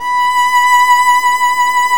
Index of /90_sSampleCDs/Roland - String Master Series/STR_Violin 1-3vb/STR_Vln1 % marc